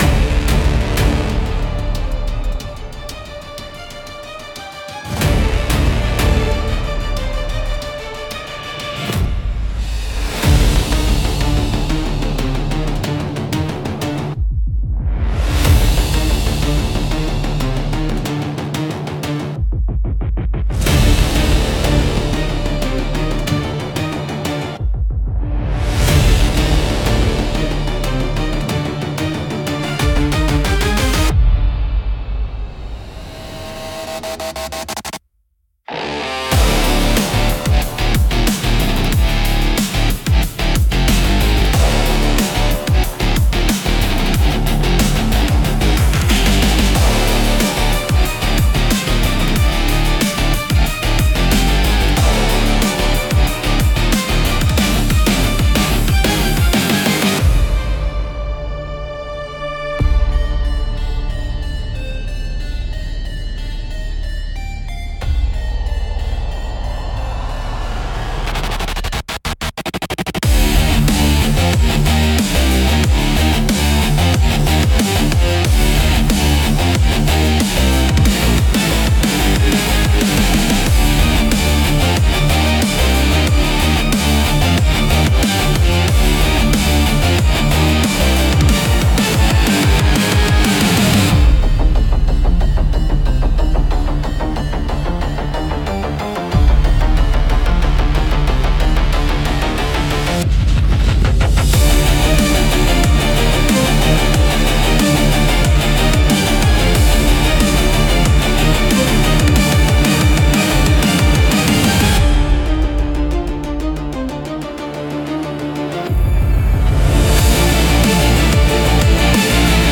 激しいリズムと力強い音色で、緊張感や切迫した状況を鋭く表現します。
オリジナルの緊迫は、迫力あるブラスセクションを中心に緊迫感を強調した曲調が特徴です。
BGM用途としては、サスペンスや戦闘、追跡劇など緊迫したシーンに最適です。
危機感や焦燥感を強調したい場面にぴったりのジャンルです。